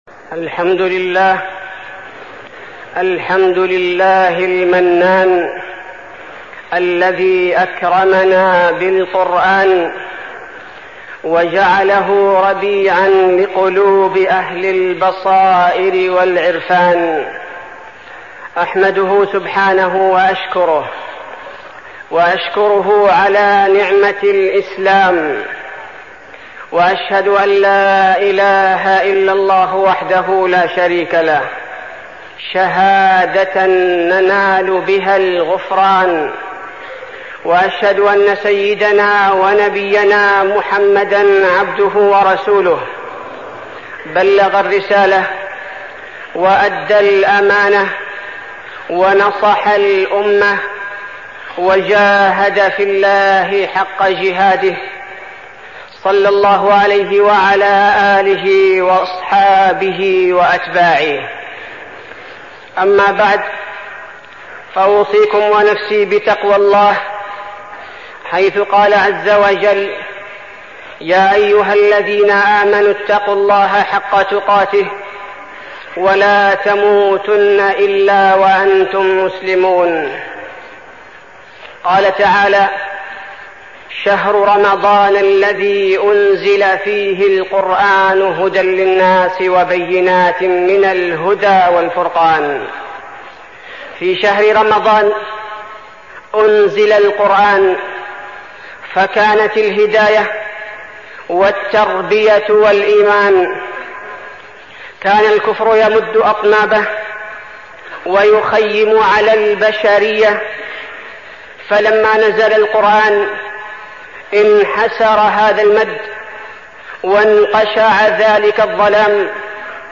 تاريخ النشر ٦ رمضان ١٤١٦ هـ المكان: المسجد النبوي الشيخ: فضيلة الشيخ عبدالباري الثبيتي فضيلة الشيخ عبدالباري الثبيتي فضل القرآن